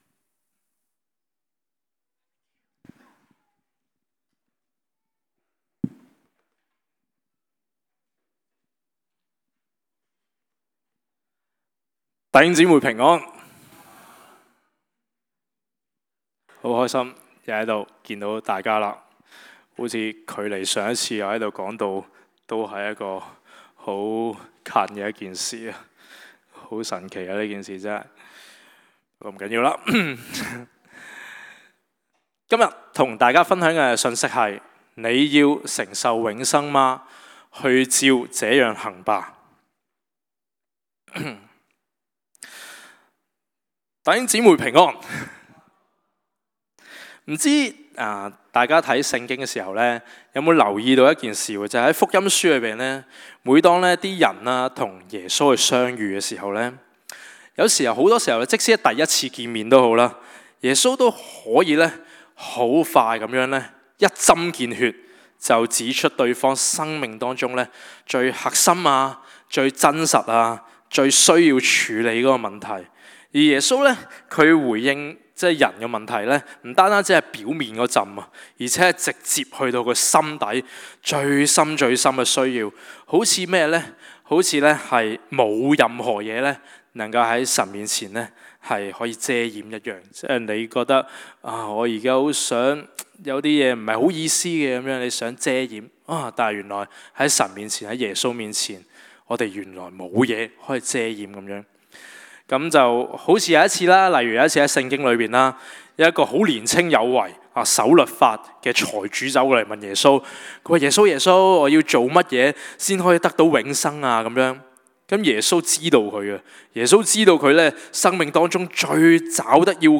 在： Sermon